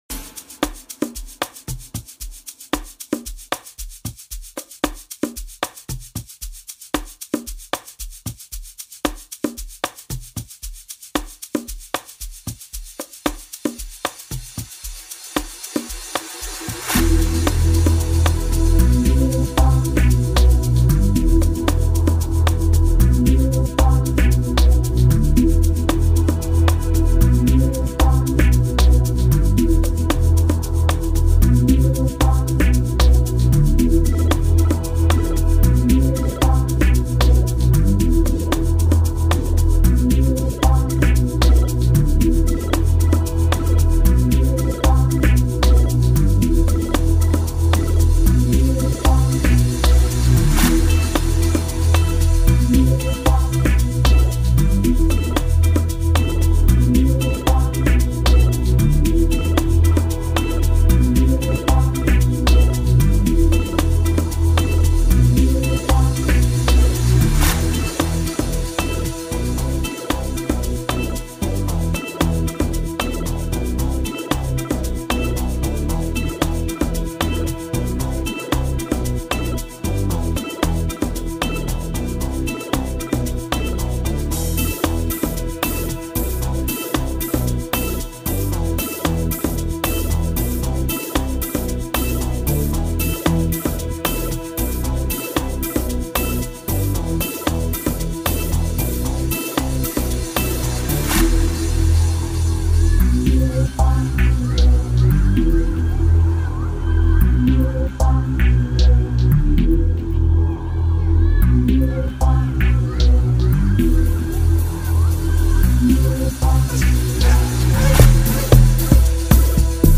Amapiano production